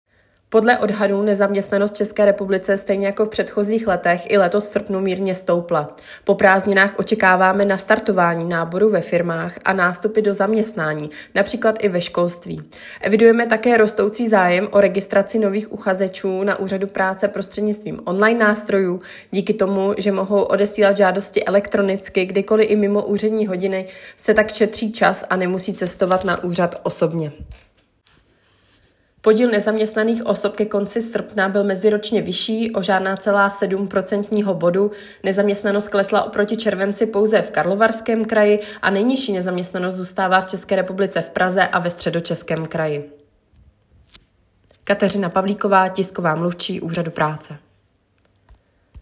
Komentář